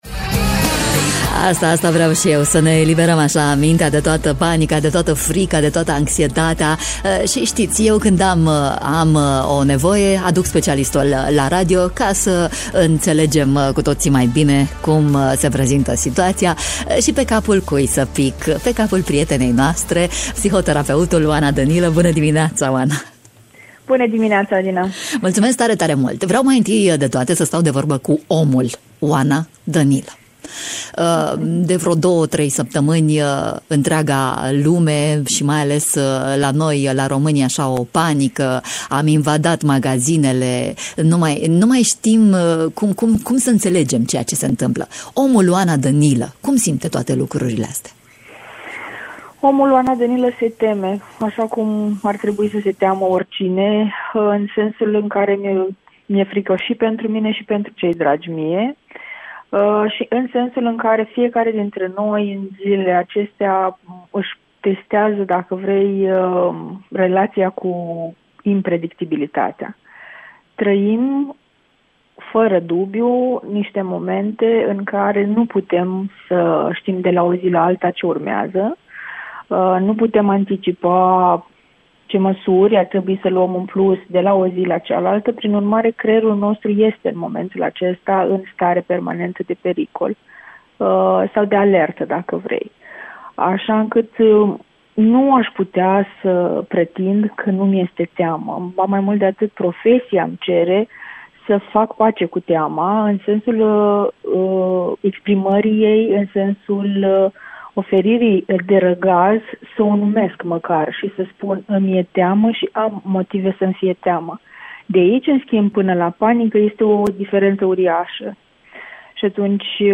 în matinalul Radio România Iași. Cum alungăm anxietatea produsă de pandemia Covid-19? Mesajul specialistului: împărtășiți teama cuiva drag și păstrați-vă calmul!